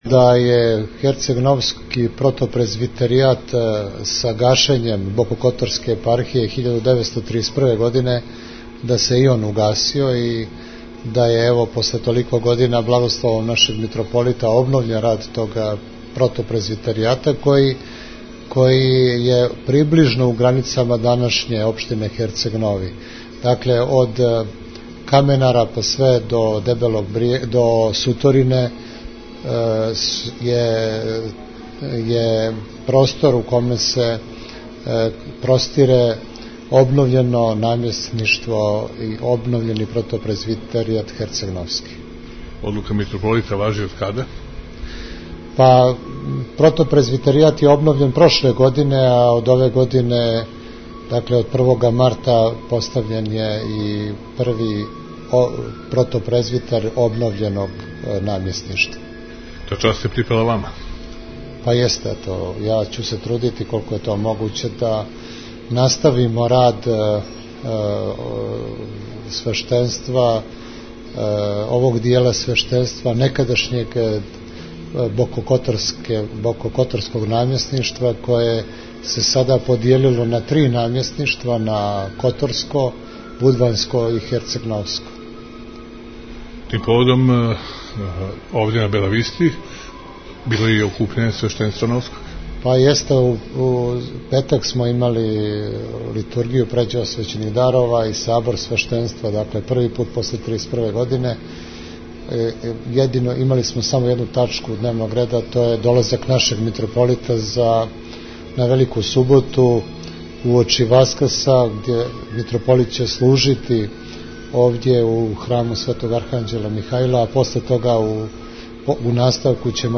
говорио за ТВ ,,Нови"